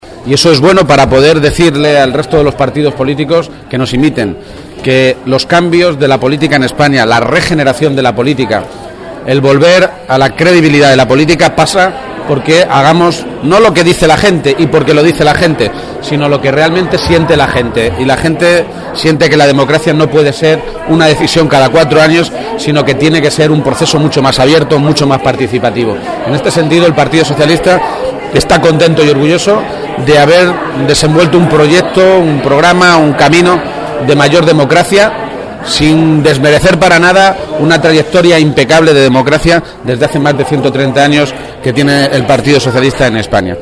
García-Page realizó estas declaraciones después de votar en la sede del PSOE de Toledo donde estuvo acompañado por su madre